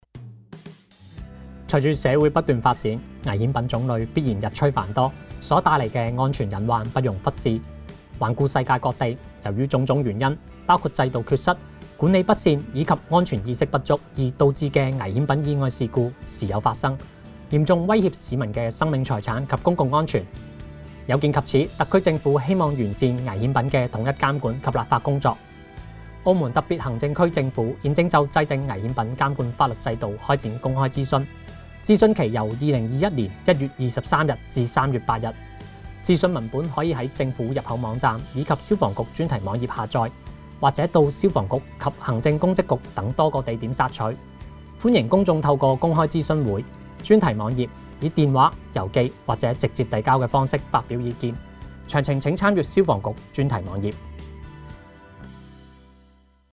電台宣傳聲帶
危險品監管法律制度_諮詢本澳電台音效檔.mp3